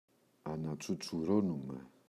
ανατσουτσουρώνουμαι [anatsutsu’ronume]